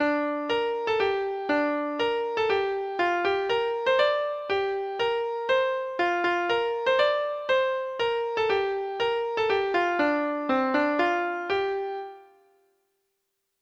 Folk Songs from 'Digital Tradition' Letter T Tiftie's Annie
Free Sheet music for Treble Clef Instrument